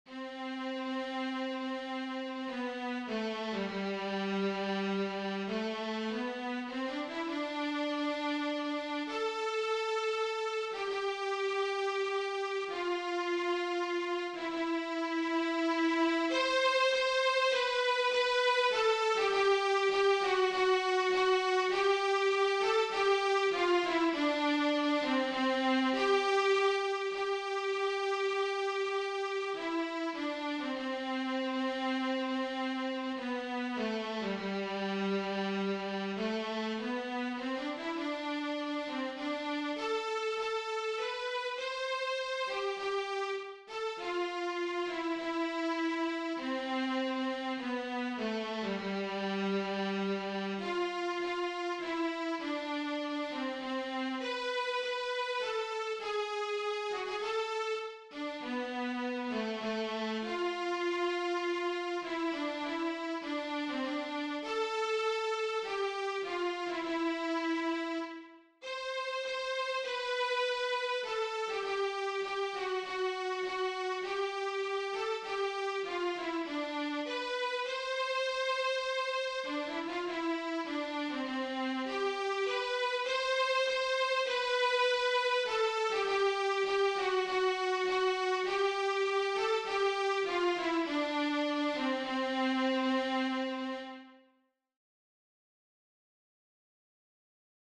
VIOLA SOLO Wedding, Baroque Music Skill level
DIGITAL SHEET MUSIC - VIOLA SOLO